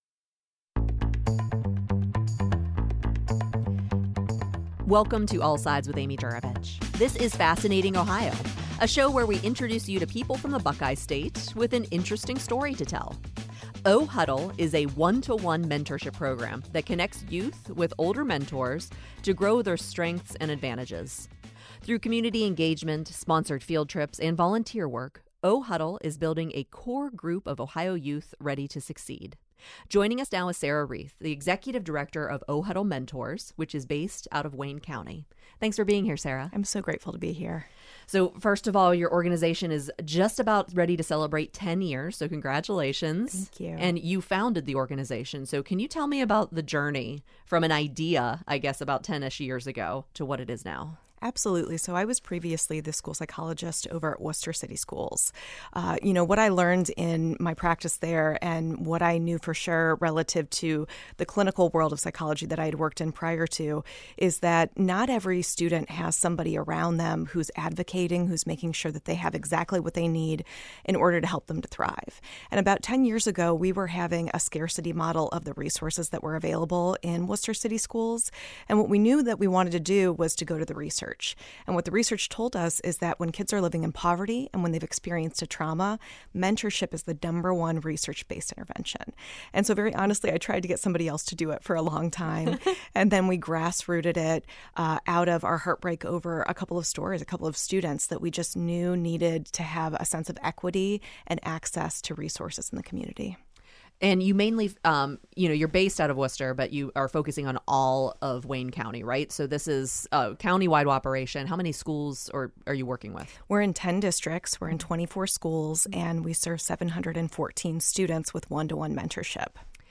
All Sides is a two-hour daily public affairs talk show designed to touch all sides of the issues and events that shape life in central Ohio.